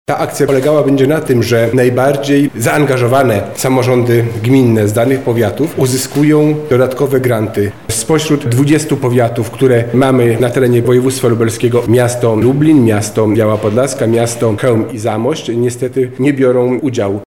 Chcemy w ten sposób zwiększyć liczbę zaszczepionych – mówi wicewojewoda lubelski Robert Gmitruczuk: